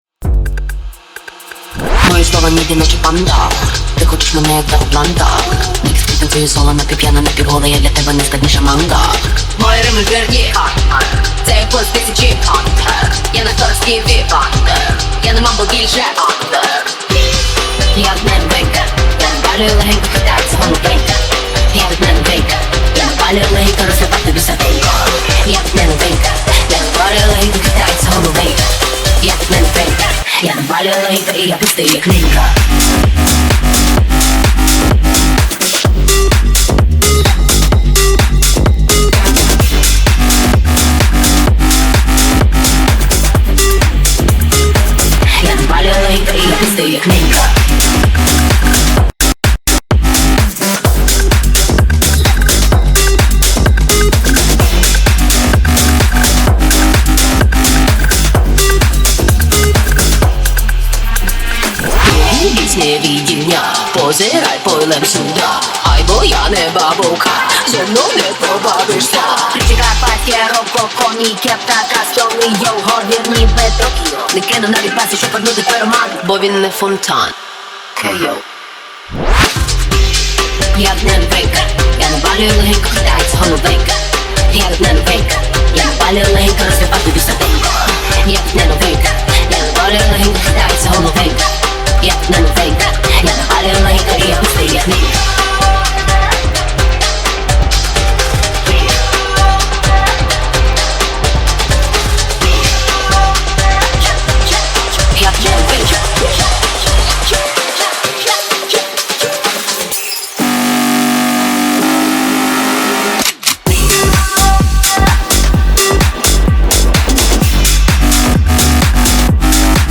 • Жанр: Electronic, EDM